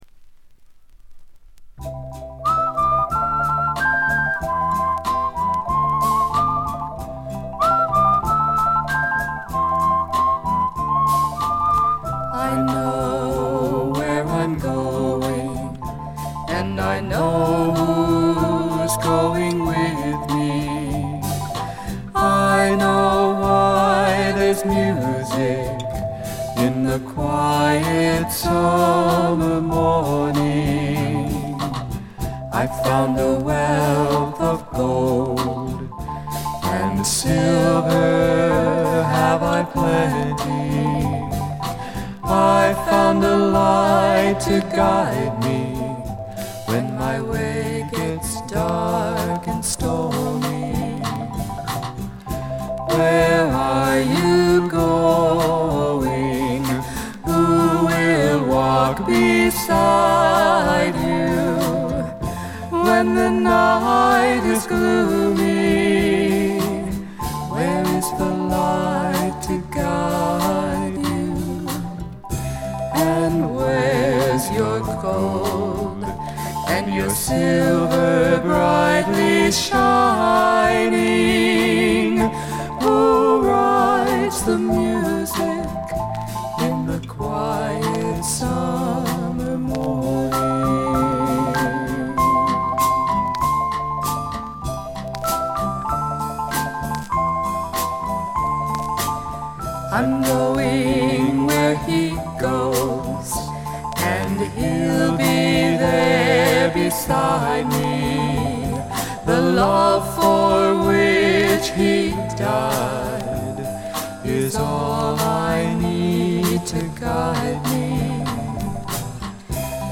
プレスのためかバックグラウンドノイズ、チリプチが出ます。
知る人ぞ知る自主制作ポップ・フォークの快作です。
試聴曲は現品からの取り込み音源です。